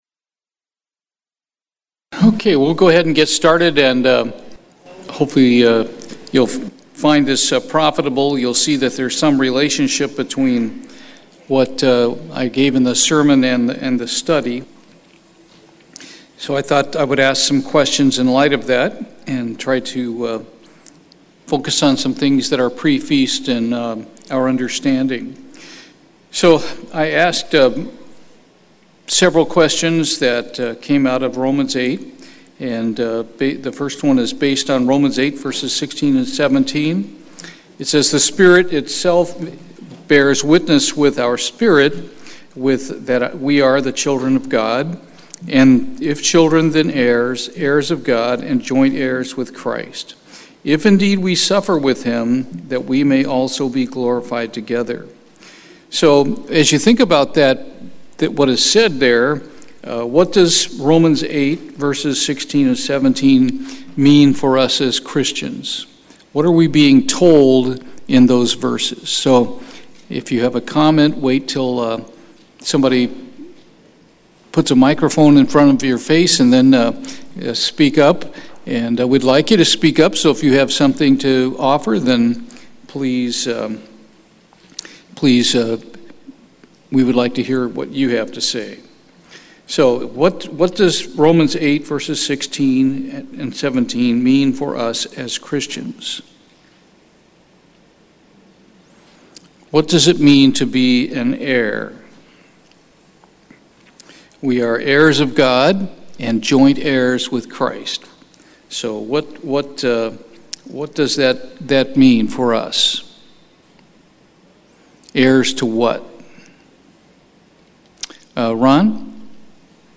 Bible Study, Heirs of what?
Given in Houston, TX